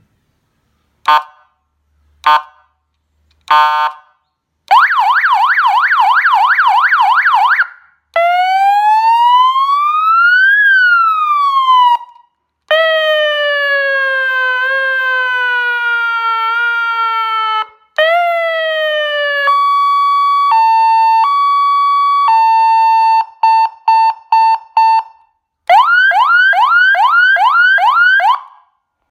7 Sound Siren With Mic. Sound Effects Free Download